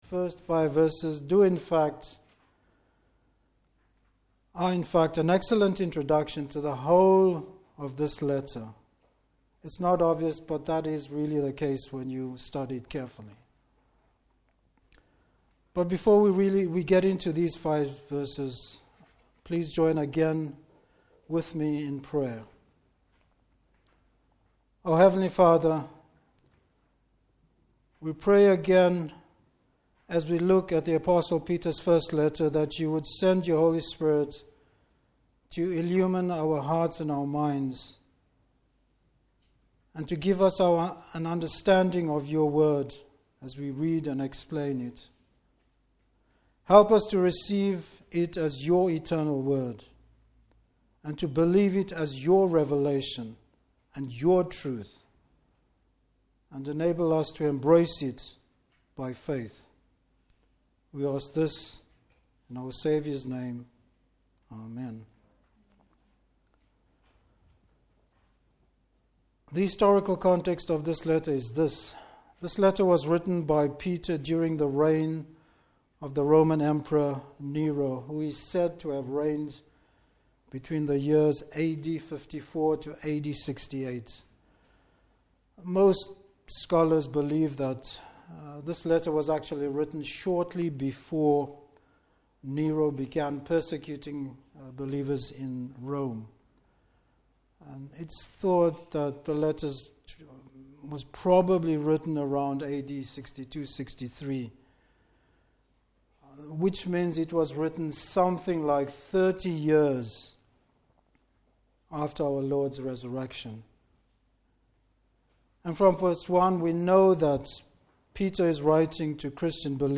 Service Type: Morning Service An introduction to the Letter.